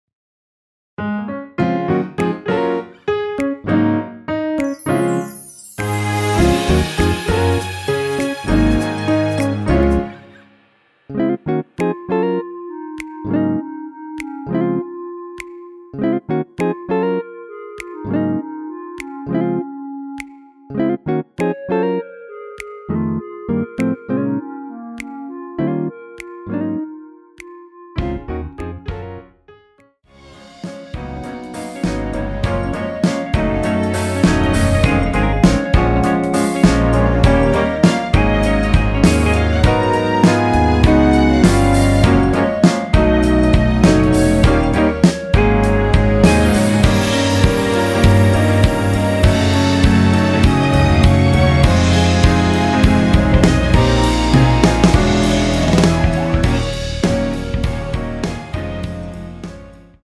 원키에서 (-2)내린 멜로디 포함된 MR 입니다.(미리듣기 참조)
Db
앞부분30초, 뒷부분30초씩 편집해서 올려 드리고 있습니다.
중간에 음이 끈어지고 다시 나오는 이유는